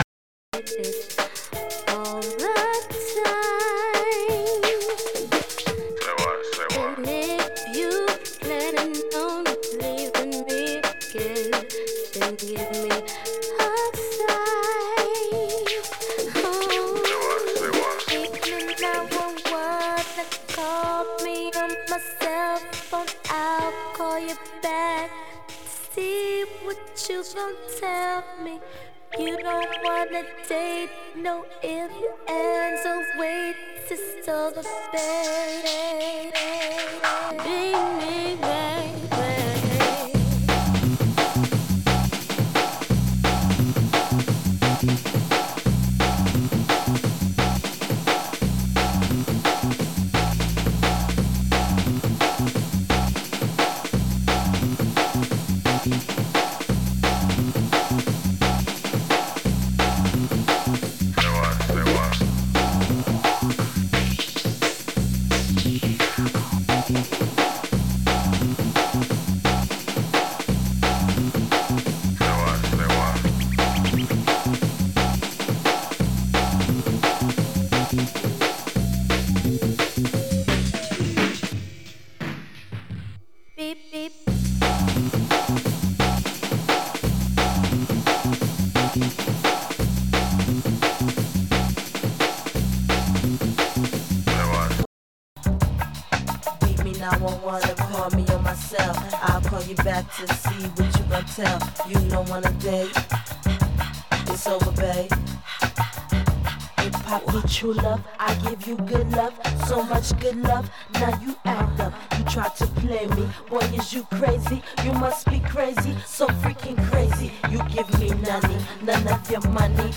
Drum N Bass , Jungle
Hip-Hop